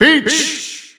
Announcer pronouncing Peach's name in Dutch.
Peach_Dutch_Announcer_SSBU.wav